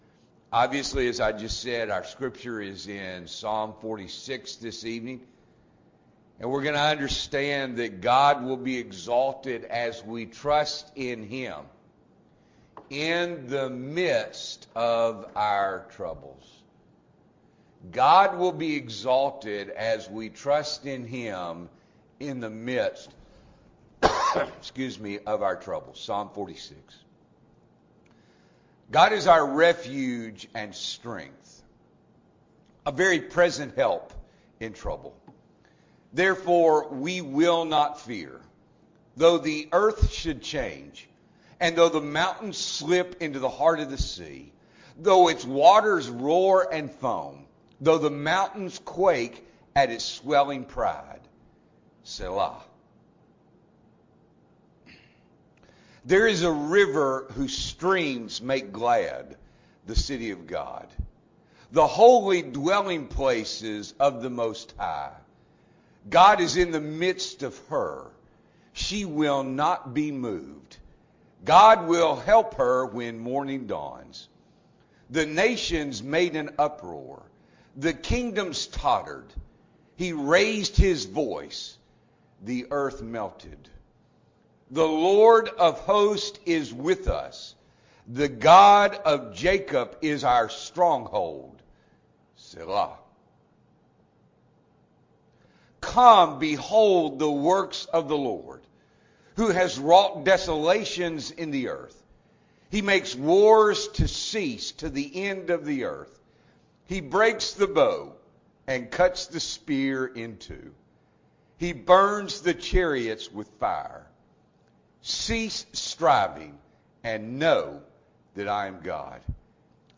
December 12, 2021 – Evening Worship